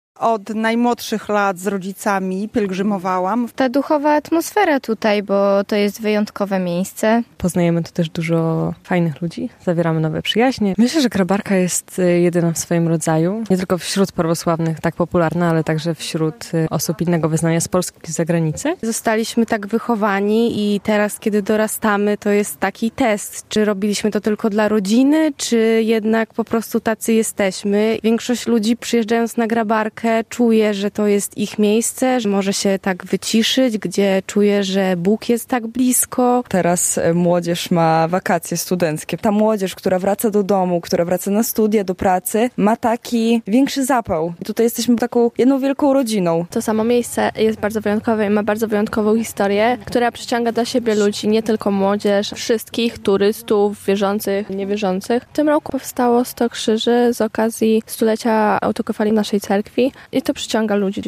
Czym Grabarka przyciąga młodych ludzi - relacja